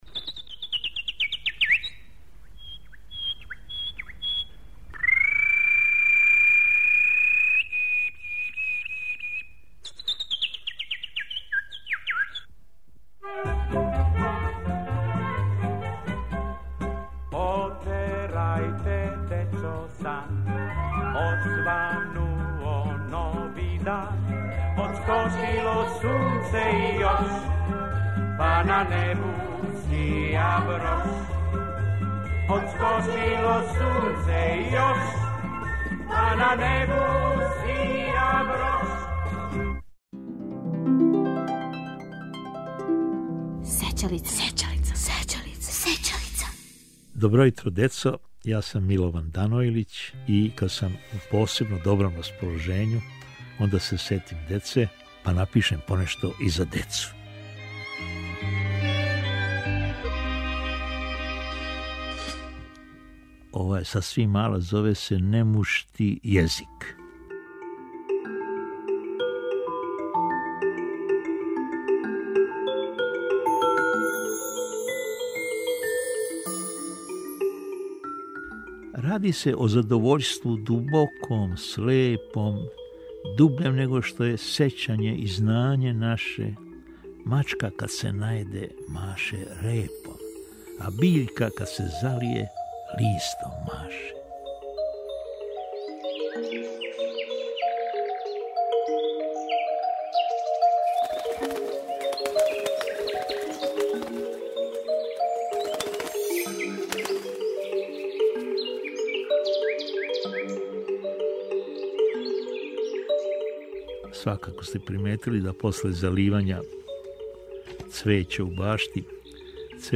Слушате глас и стихове великог песника за децу, Милована Данојлића.